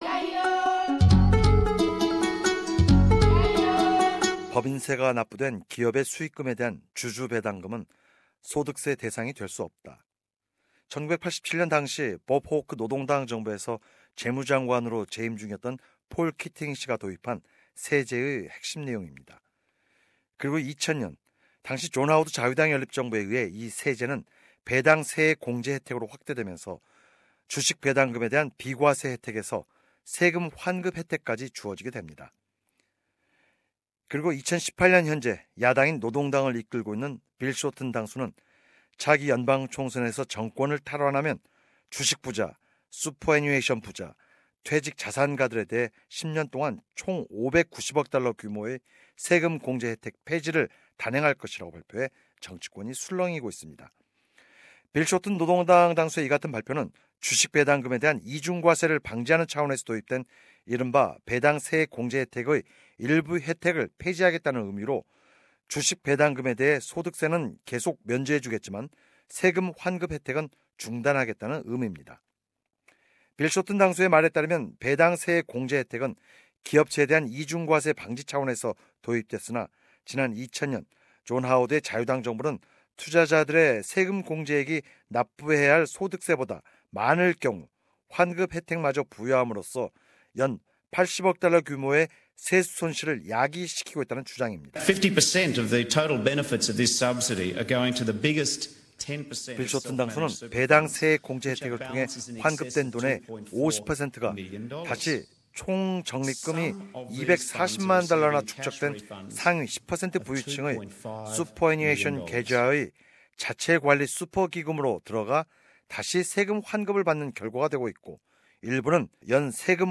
[자세한 내용은 오디오 뉴스를 통해 접하실 수 있습니다.